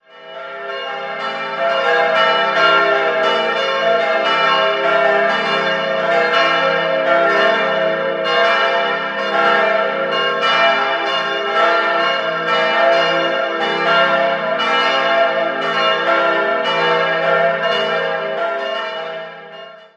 Das Gotteshaus ist als Zelt gestaltet und besitzt eine riesige Fensterfläche in Richtung Westen, der Kirche vorgelagert ist der niedrige Beton-Glockenträger.
Idealquartett: e'-g'-a'-c'' Die Glocken wurden 1972 von der Glockengießerei Karl Stumpf in Heidelberg gegossen.